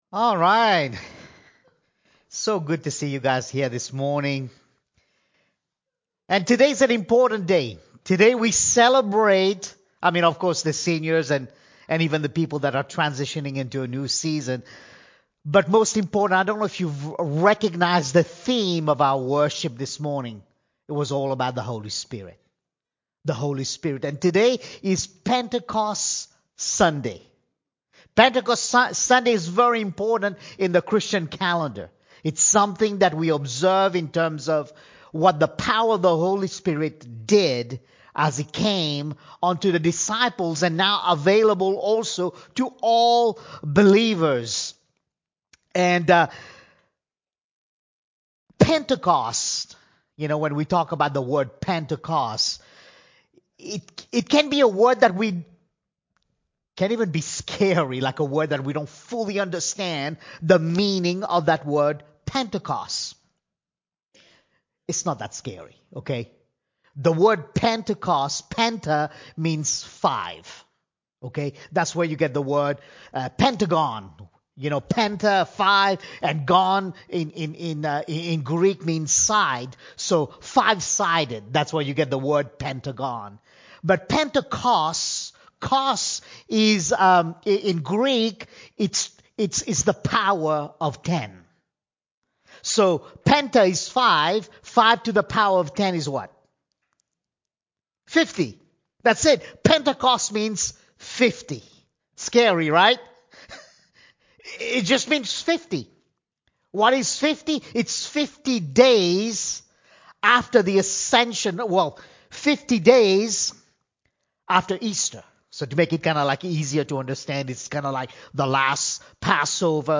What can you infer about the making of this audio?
Stay & Wait – Pentecost Sunday – Ocean Church